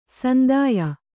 ♪ sandāya